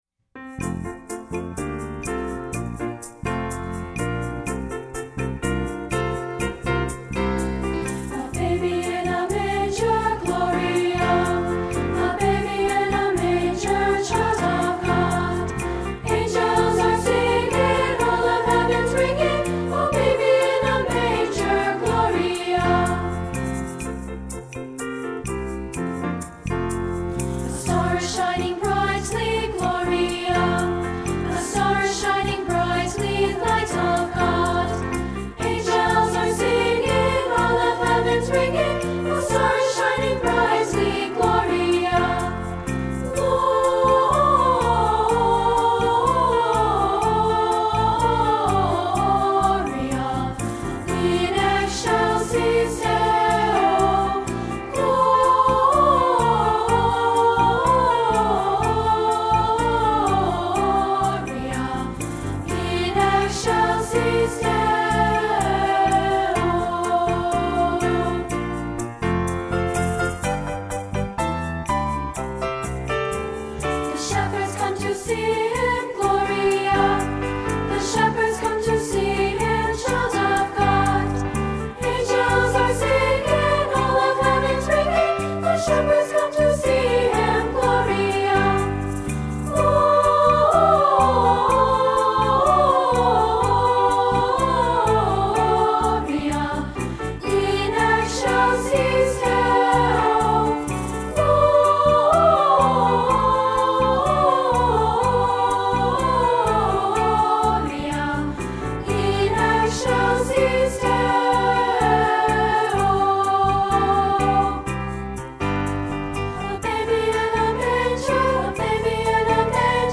Voicing: SA